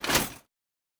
pgs/Assets/Audio/Fantasy Interface Sounds/Foley Armour 08.wav at master
Foley Armour 08.wav